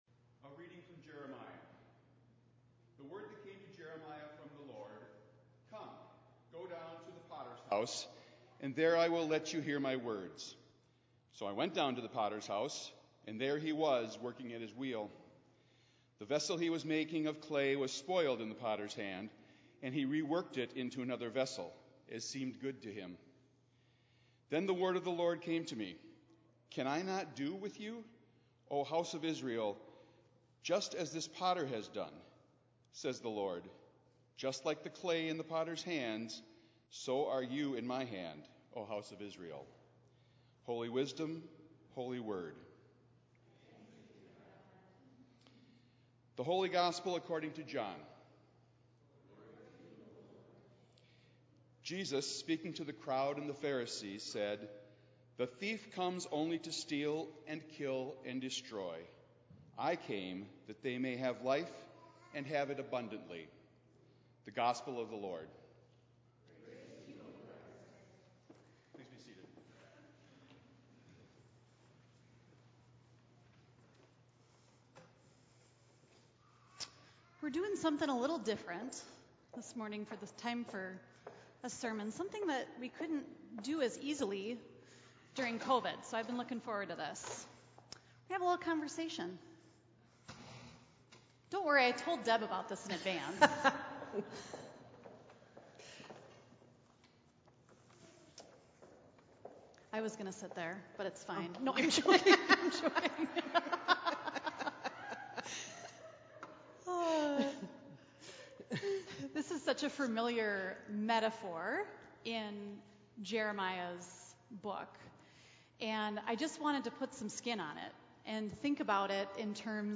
Minnetonka Livestream · Sunday, June 26, 2022 9:30 am